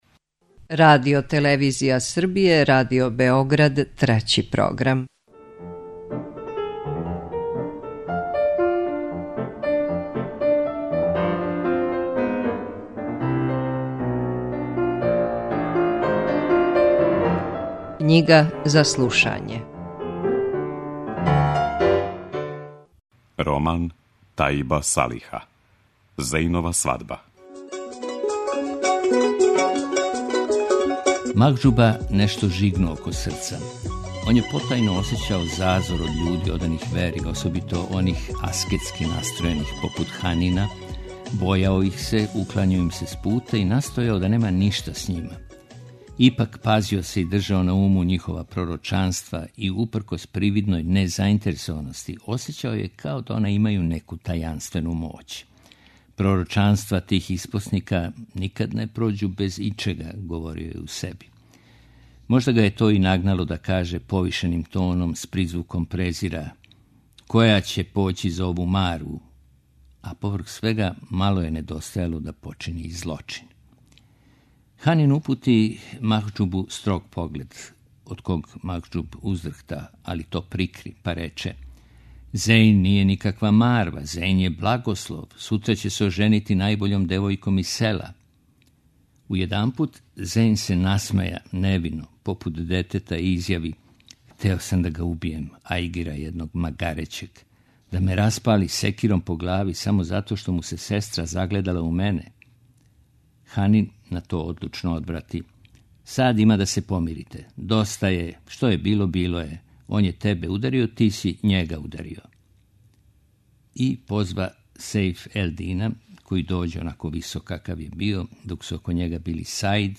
У циклусу КЊИГА ЗА СЛУШАЊЕ, можете пратити пети наставак романа 'Зејнова свадба' чији је аутор Тајиб Салих.